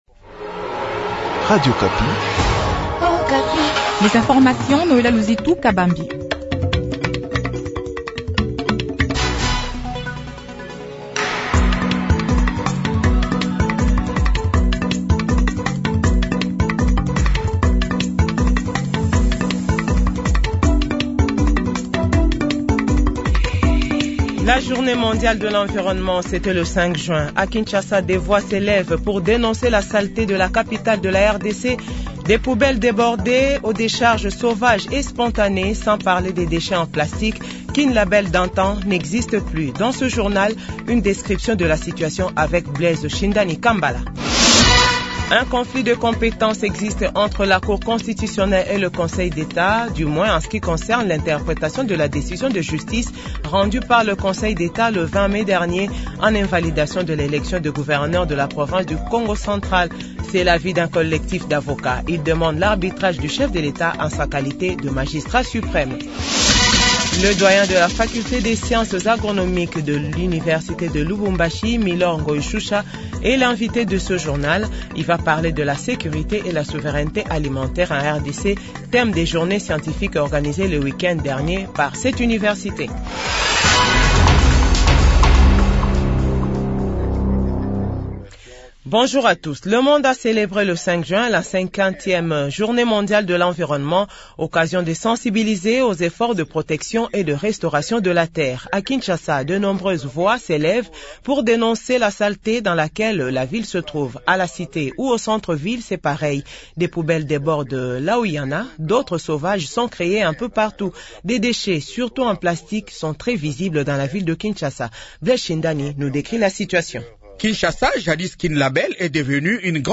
JOURNAL FRANÇAIS 8H00